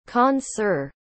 Faizal Khan (born 1993), known professionally as Khan Sir (pronounced [ˈxɑːn sɪɽ]